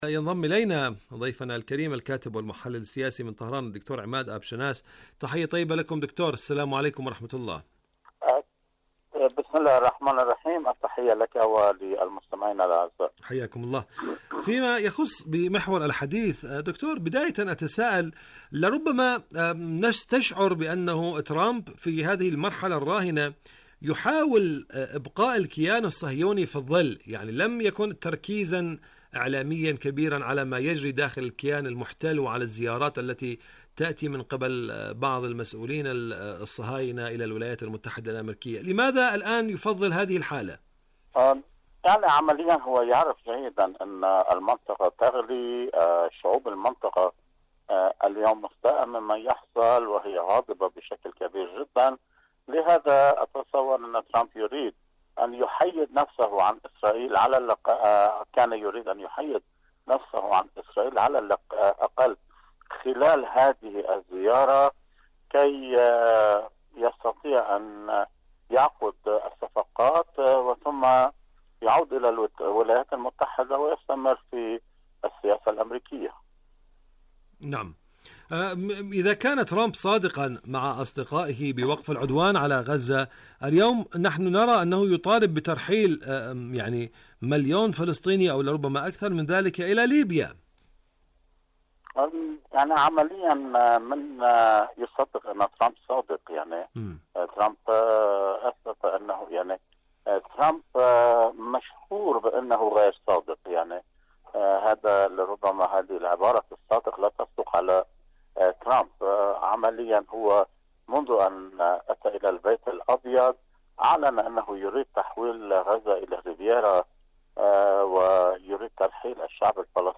برنامج حدث وحوار مقابلات إذاعية